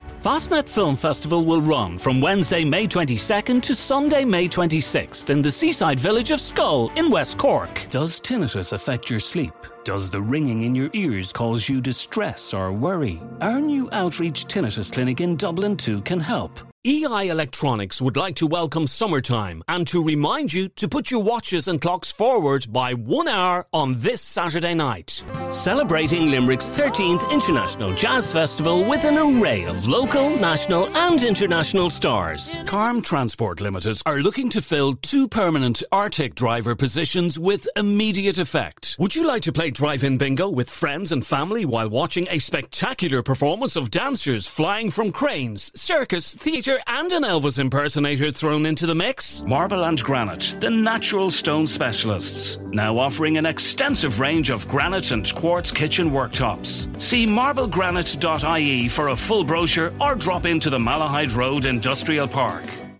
Commercial Demo
My accent is neutral english and my voice is fresh, warm, engaging and believable.
Soundproof studio and booth